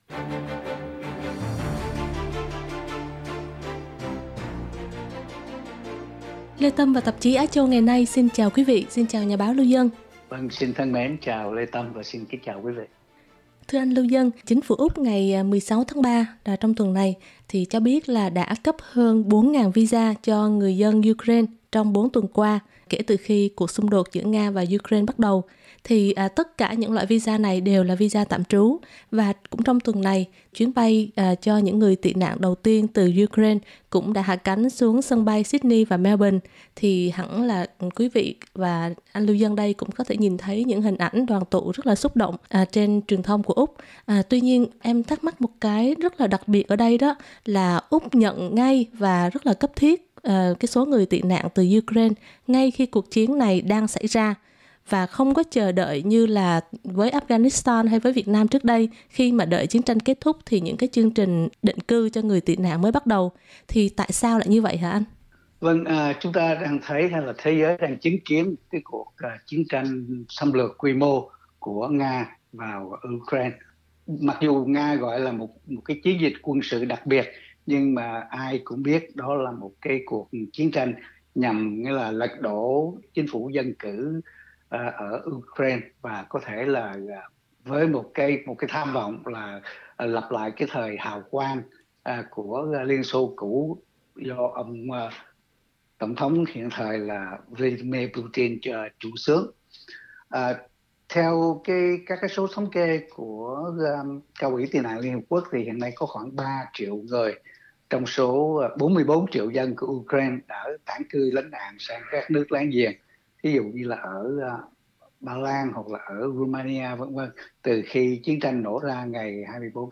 bình luận